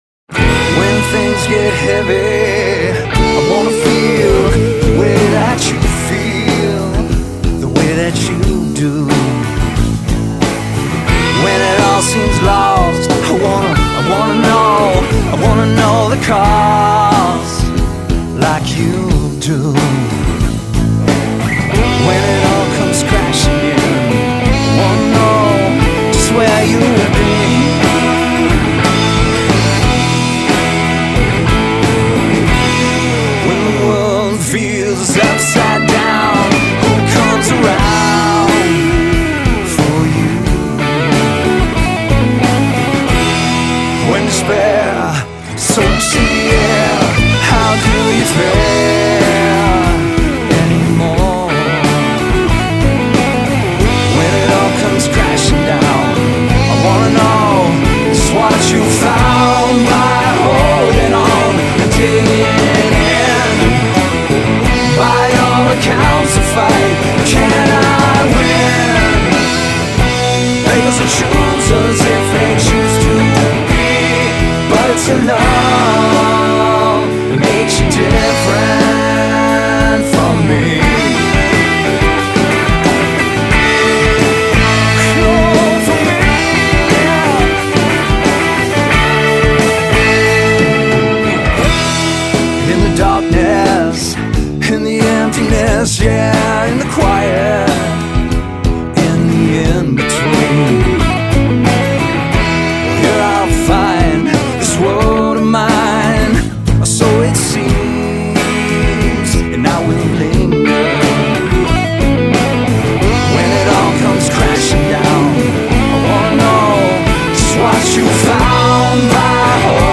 Alternative Rock, Acoustic Rock, Modern Rock